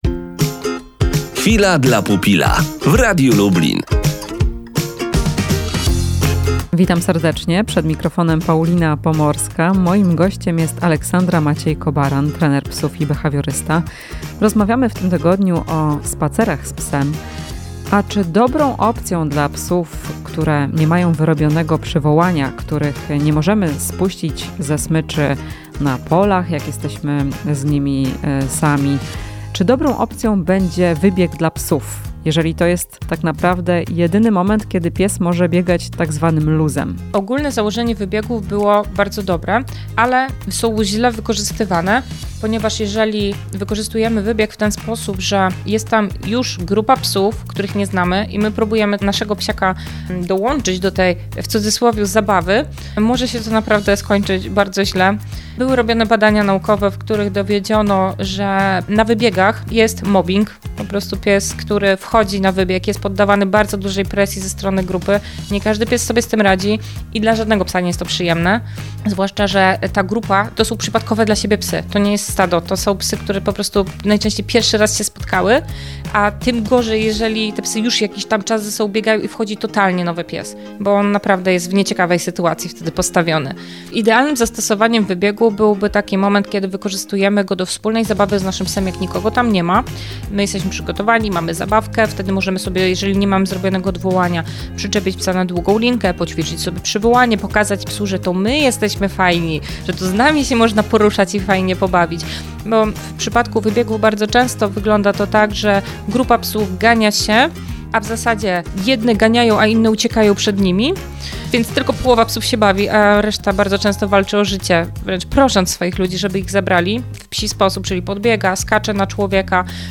Rozmowa z trenerem psów i behawiorystą